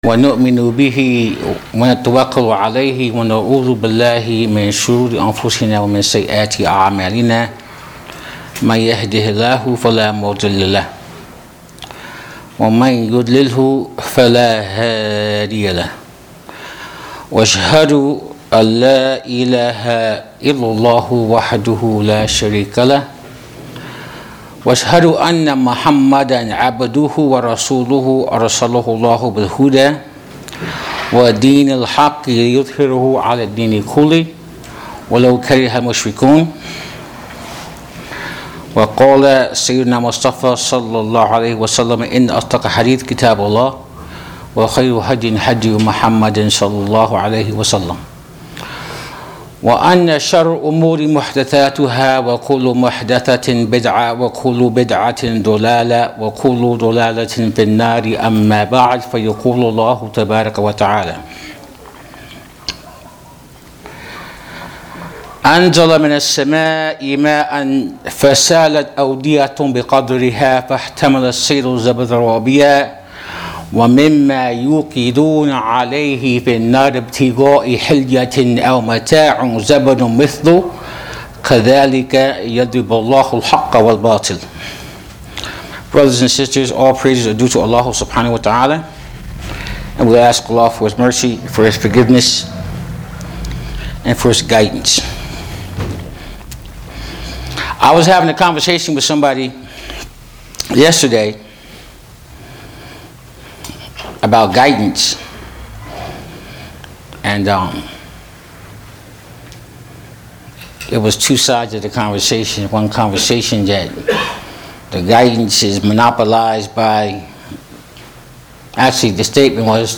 Audio Khutba